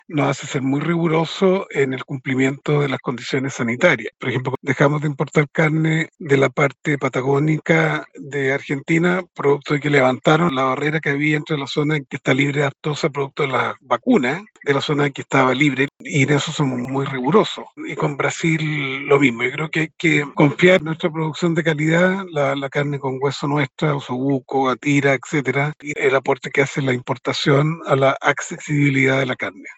En conversación con Radio Bío Bío, el ministro afirmó que Brasil es socio comercial clave, con intercambio de vino, carne y pollo, y que los envíos se reactivaron tras una pausa sanitaria.